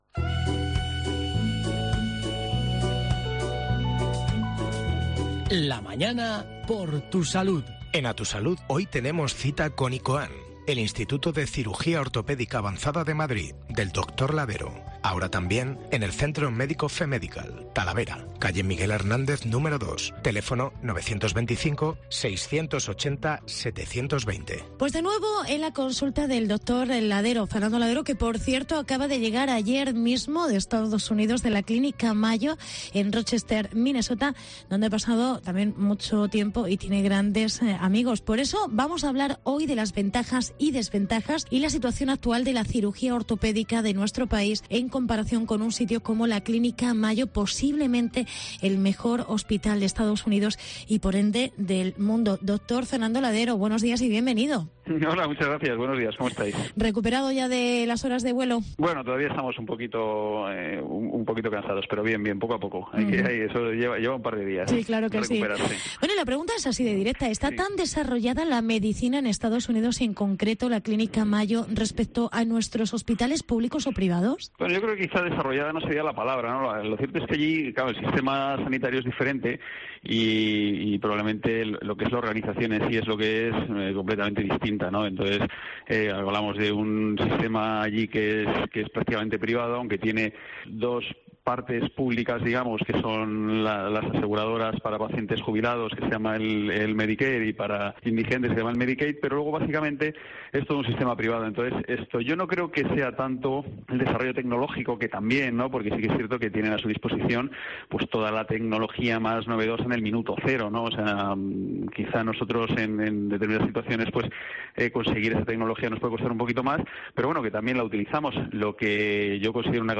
Escucha la entrevista y averiguarás qué ventajas y desventajas tienen los unos y los otros y si hay diferencia en la tecnología que utilizan y en su sistema de organización.